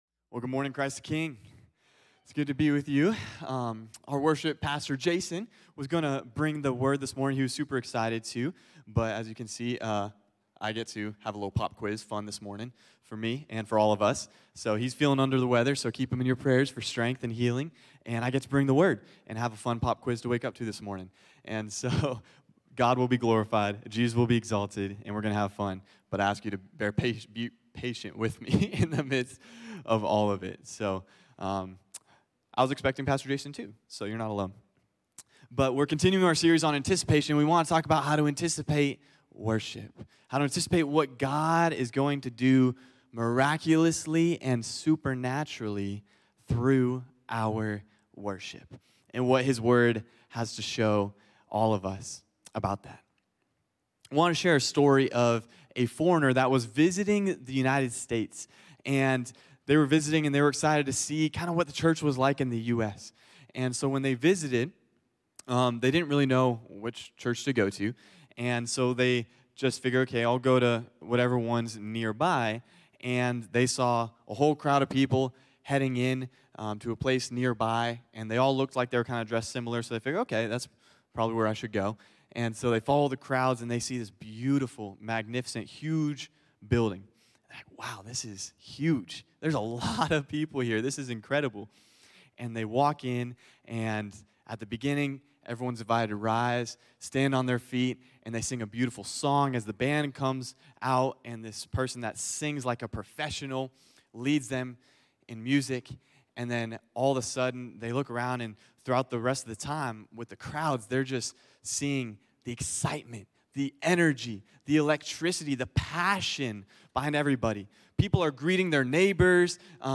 CtK-Sermon-Audio.mp3